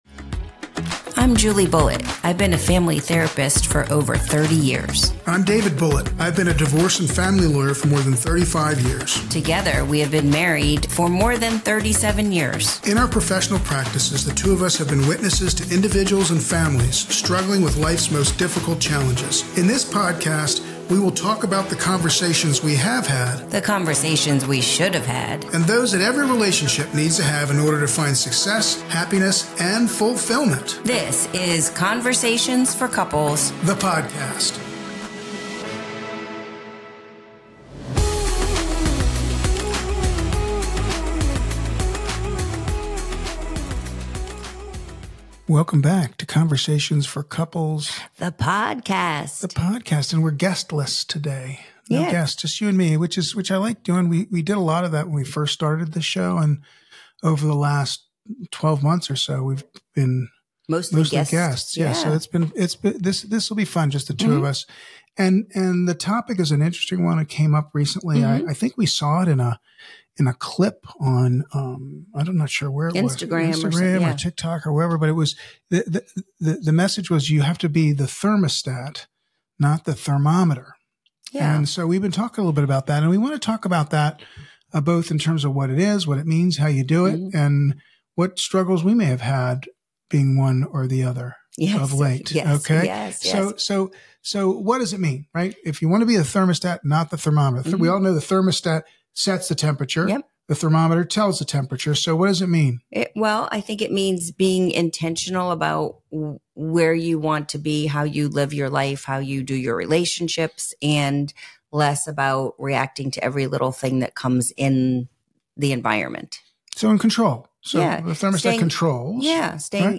guest-free conversation about emotional regulation, communication, and how to respond instead of react in your relationship